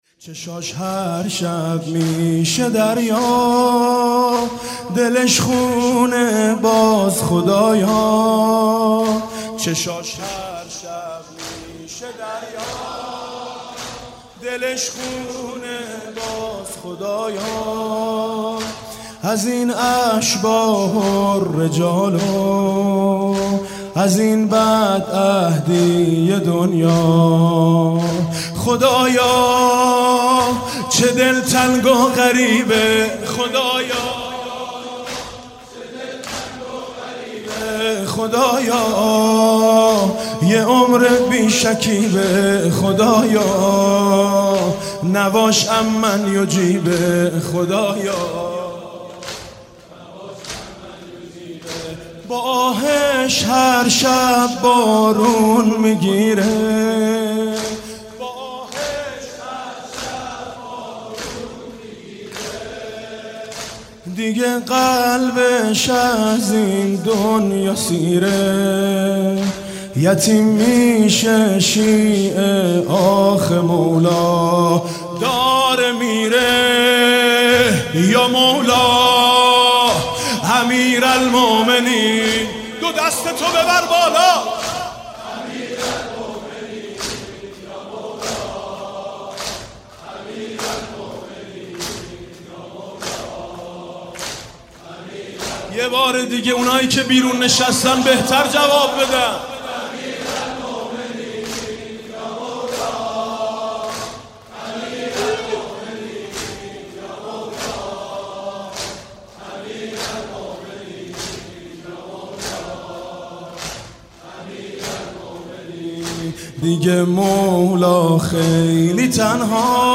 زمینه: نه مالک مونده نه سلمان، شده ذکرش أین عمار